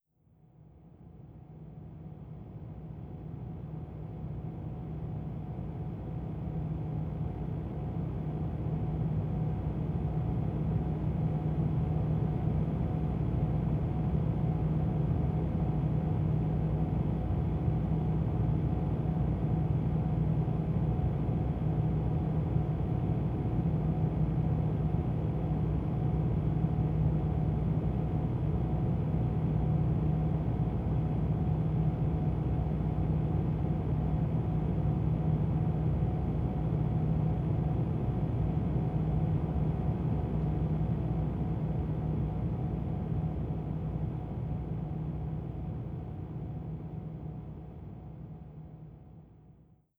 ServerFans.wav